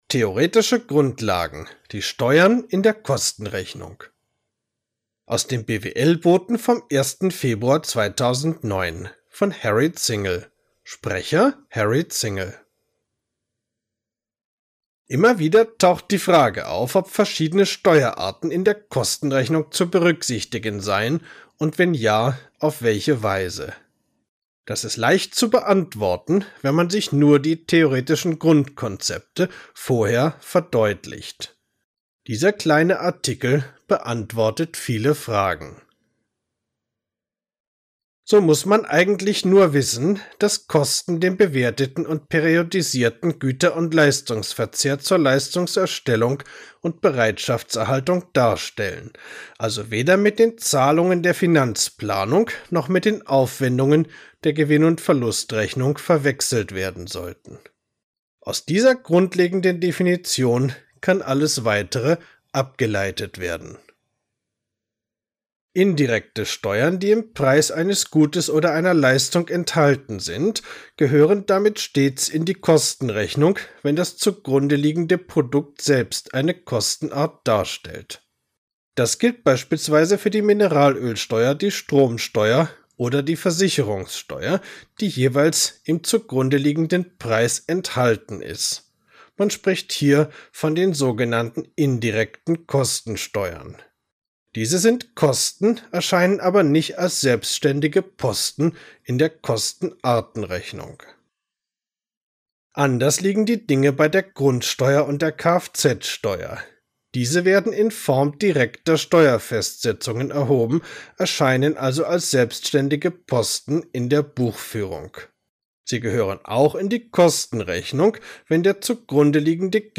Artikel als MP3 anhören, gesprochen vom Autor: